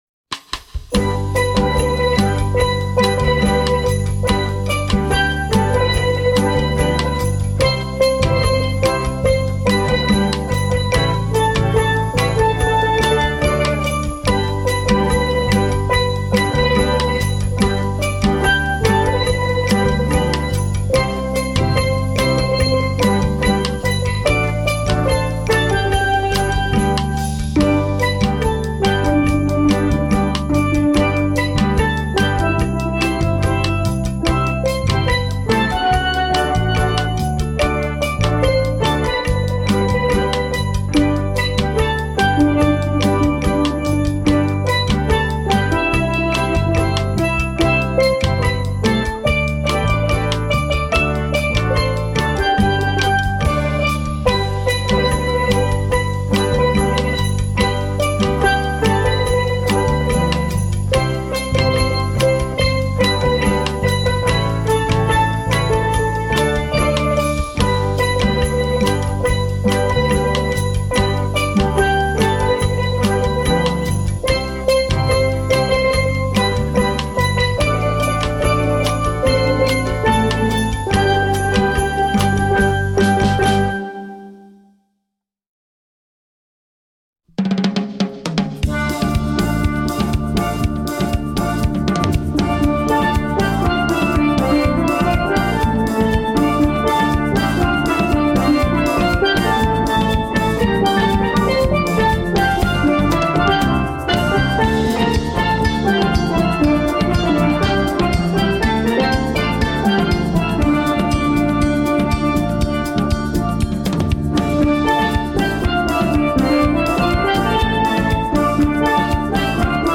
Voicing: Percussion Nonet